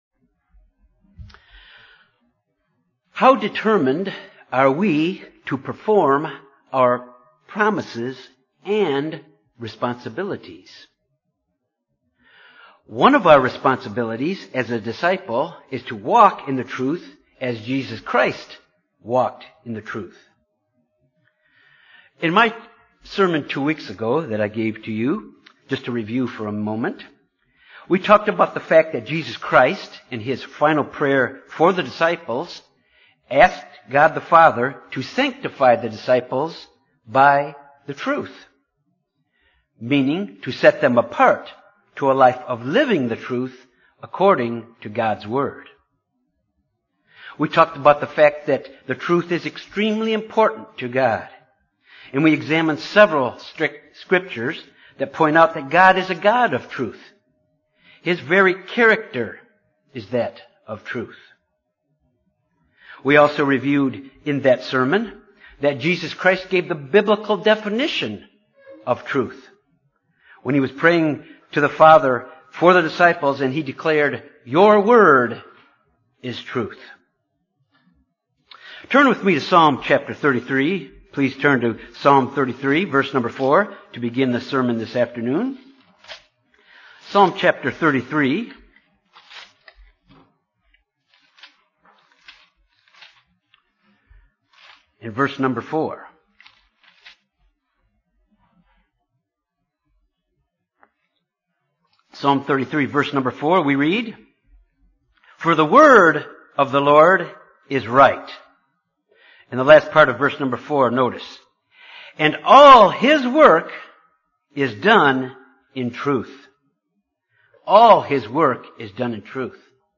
Sermons
Given in Jonesboro, AR